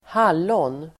Ladda ner uttalet
Uttal: [²h'al:ån]